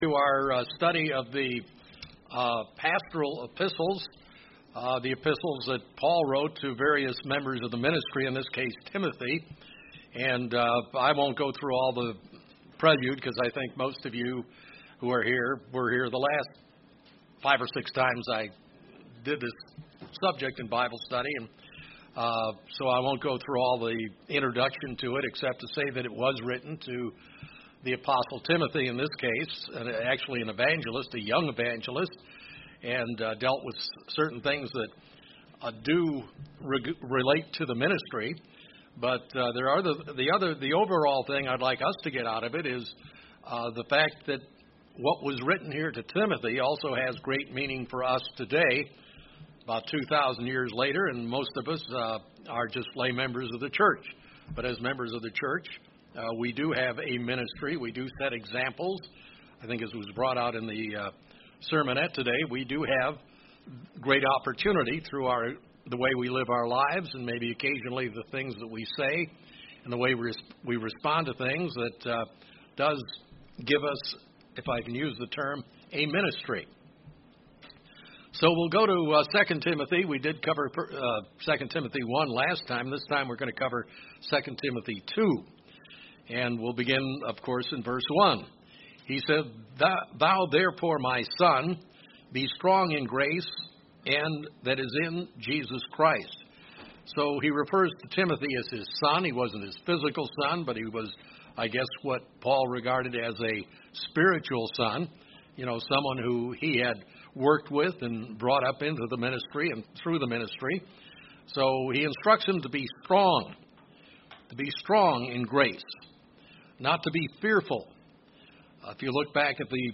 Bible study looking into the second chapter of 2 Timothy.
Given in Springfield, MO